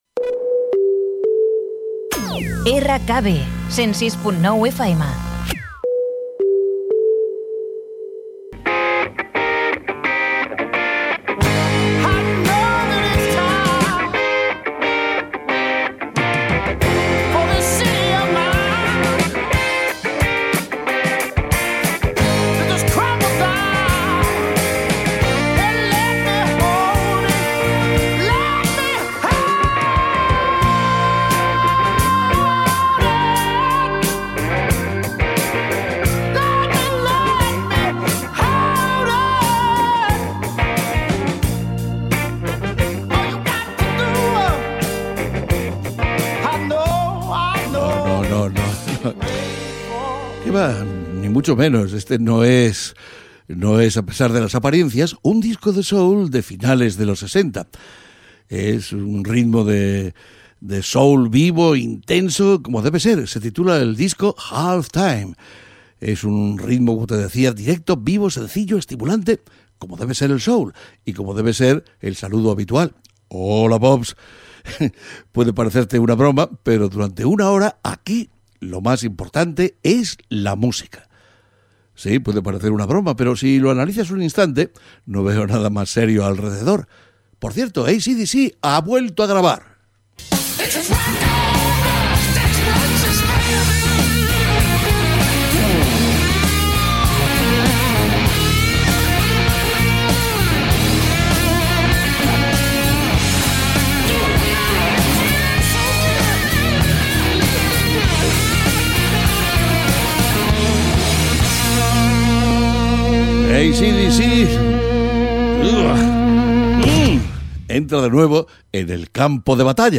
Indicatiu de l'emissora, tema musical, presentació i més temes musicals
Musical
FM